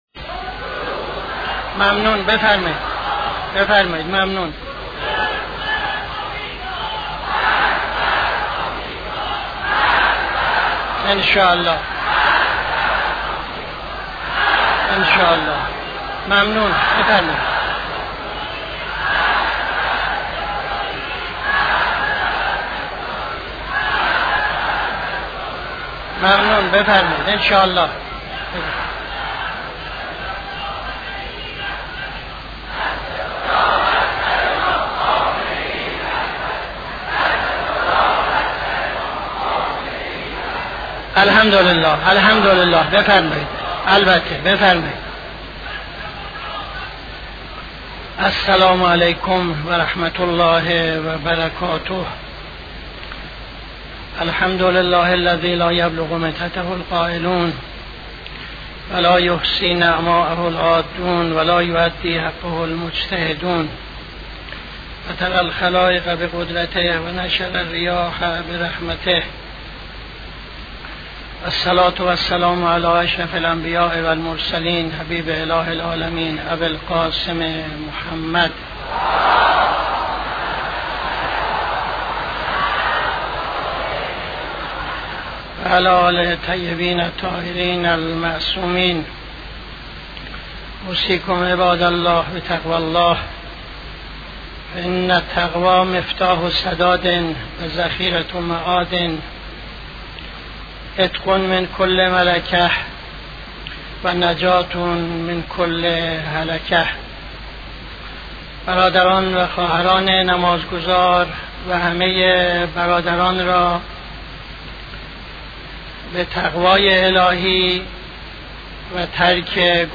خطبه اول نماز جمعه 21-12-76